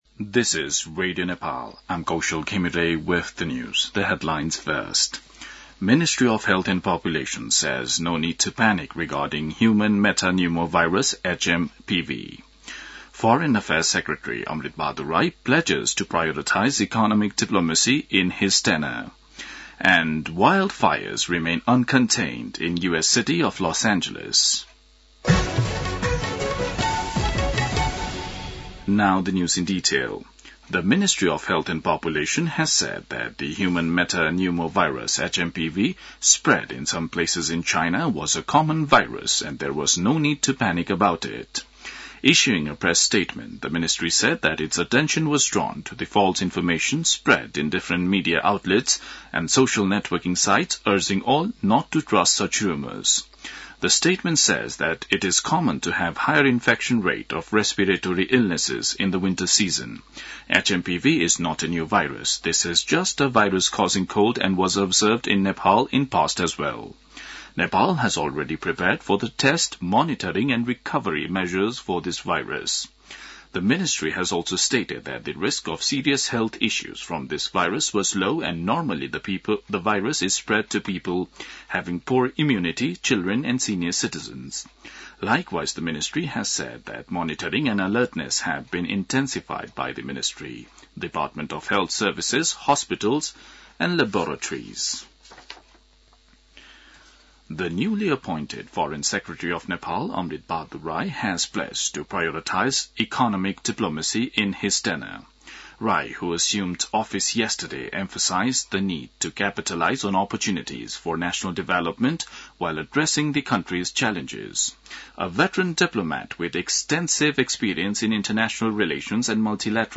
An online outlet of Nepal's national radio broadcaster
दिउँसो २ बजेको अङ्ग्रेजी समाचार : २६ पुष , २०८१